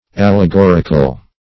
Allegoric \Al`le*gor"ic\, Allegorical \Al`le*gor"ic*al\, a. [F.